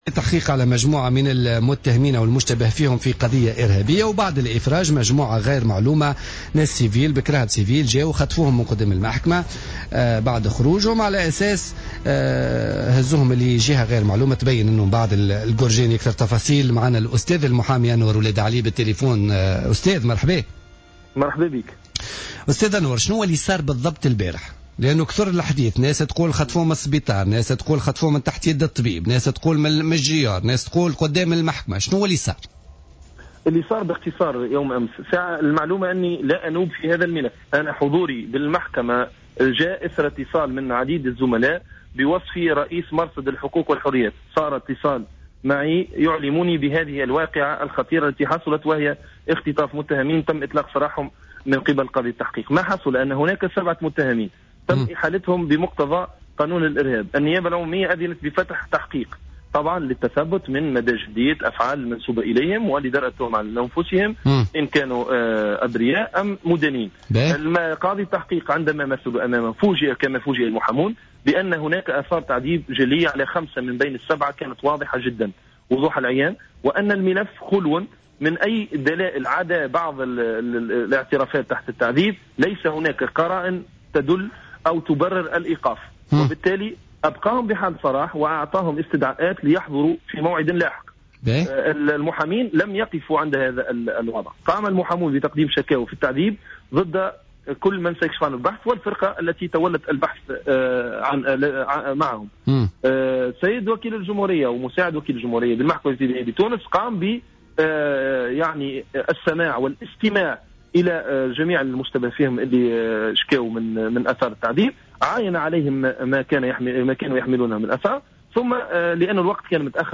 مداخلة له في بوليتيكا